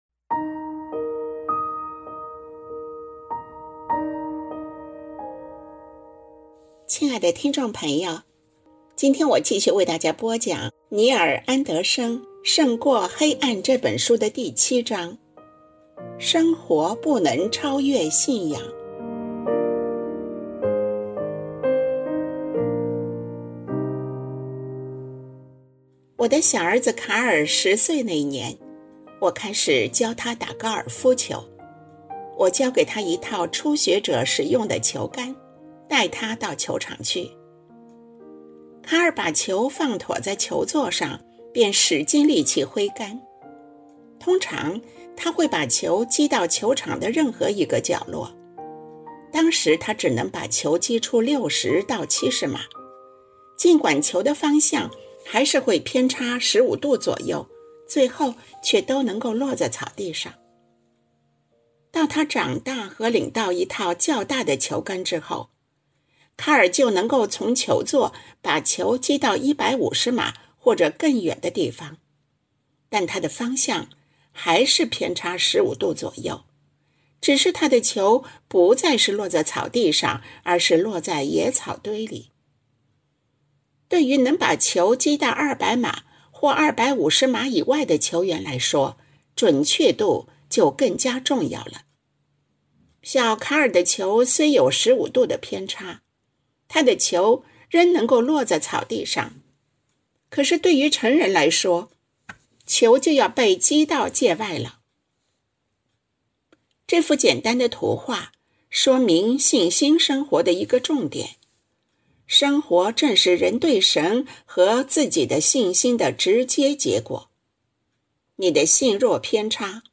作者：尼尔·安德生 亲爱的听众朋友，今天我继续为大家播讲尼尔·安德生《胜过黑暗》的